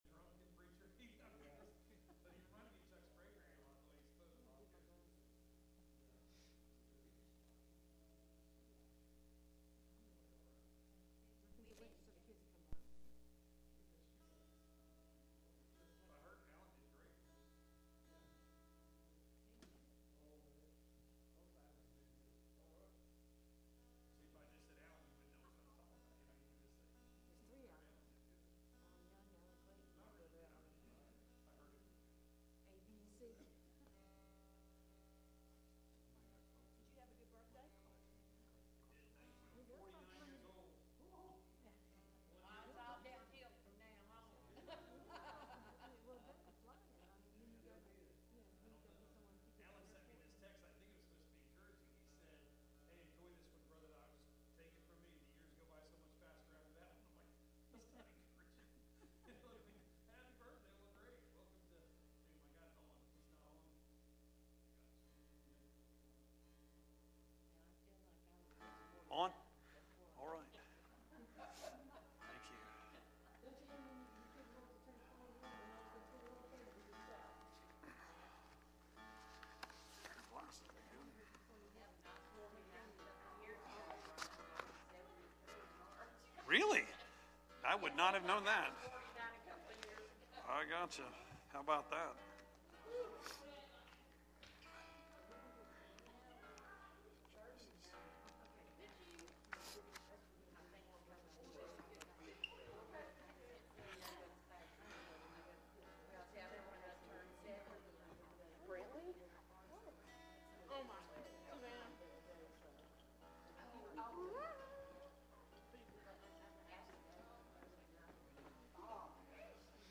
Passage: Genesis 40 Service Type: Midweek Meeting